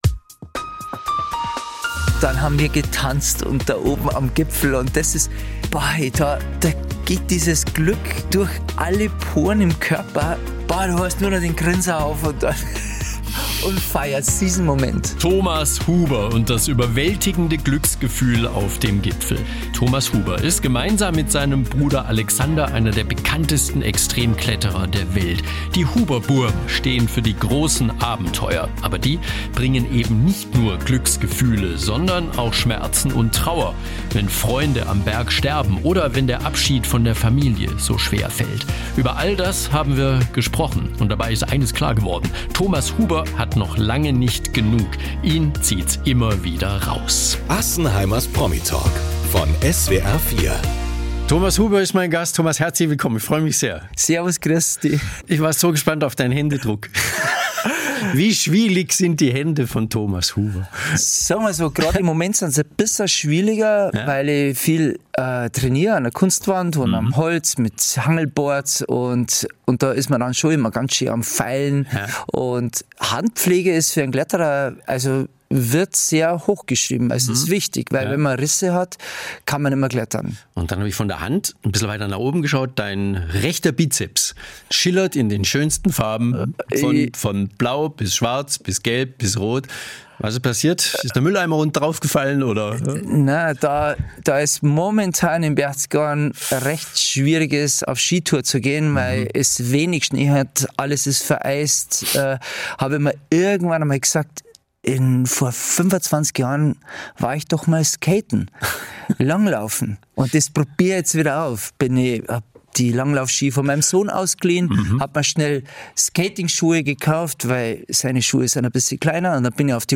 Extrembergsteiger Thomas Huber spricht im Interview über den tragischen Tod seiner engen Freundin Laura Dahlmeier und das Bergsteigen, das schnell lebensgefährlich werden kann.